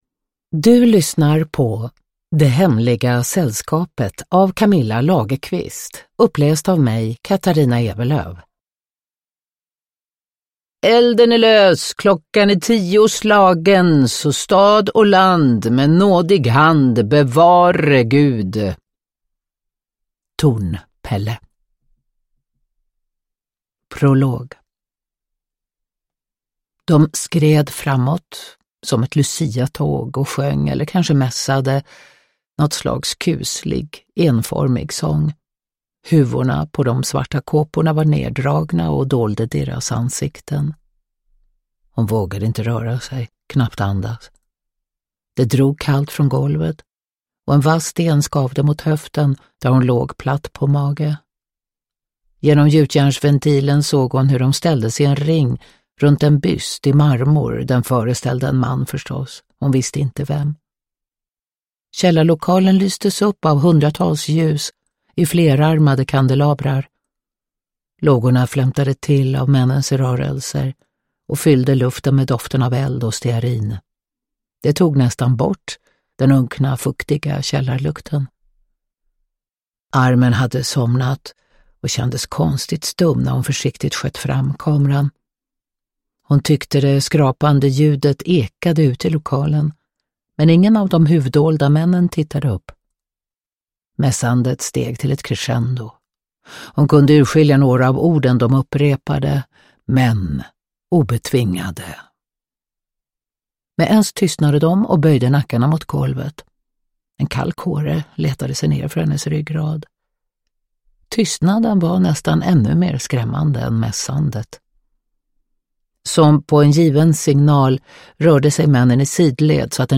Det hemliga sällskapet – Ljudbok
Uppläsare: Katarina Ewerlöf